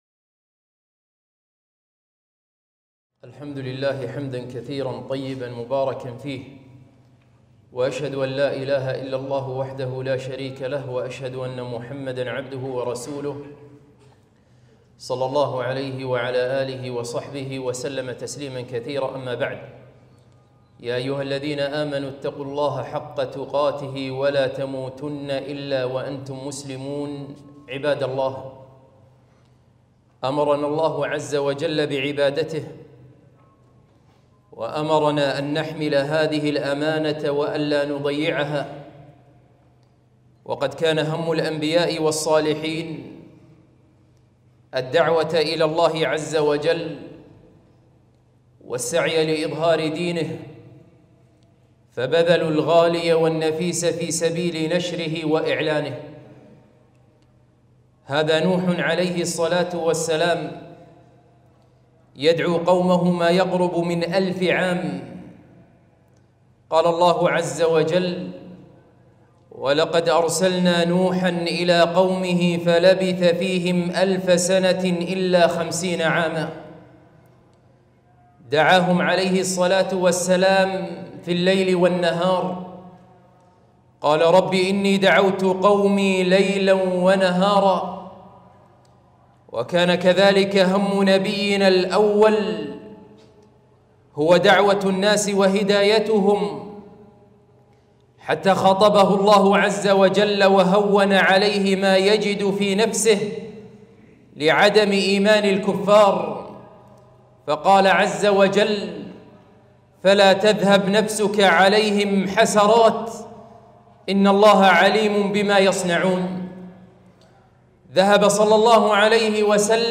خطبة - هل أنت من أصحاب الاعتذارات؟